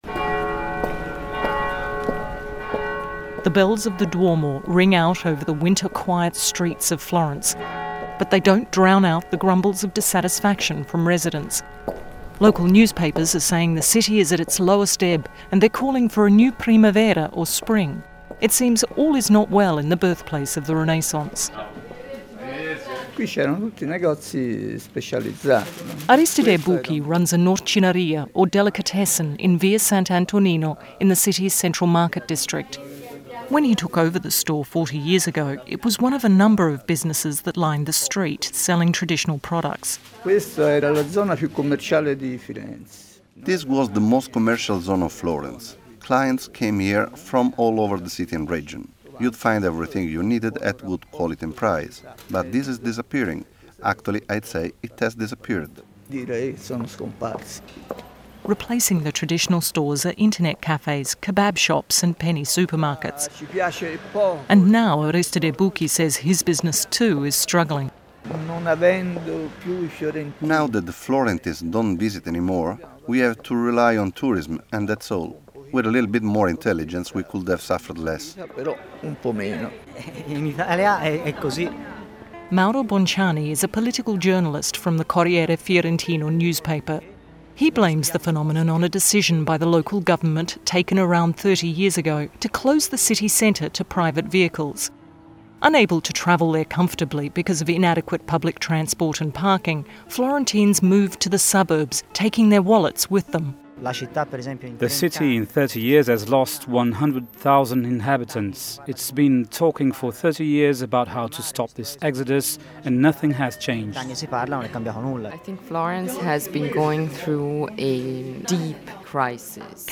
Deutsche Welle Radio “Inside Europe” feature story